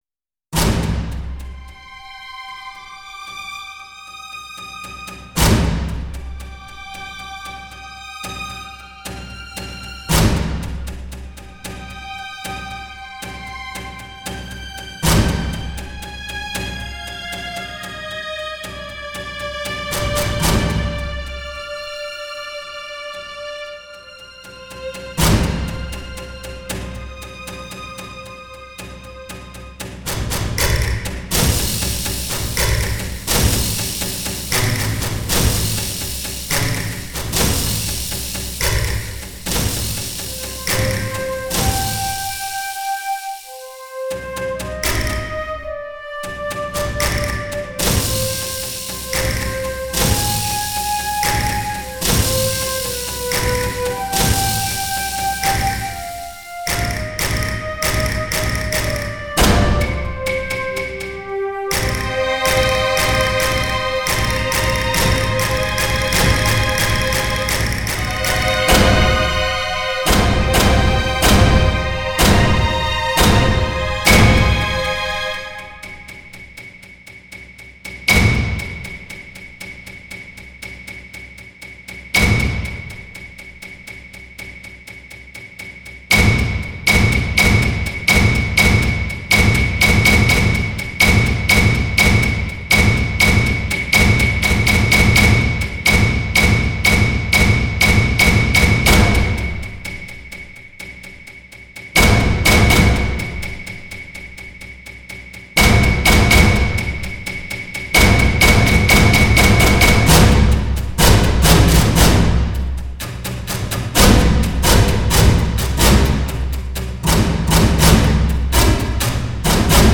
string orchestra
trumpets
transverse flutes
percussion
clarinet
orchestral work in 7 pieces (21:15)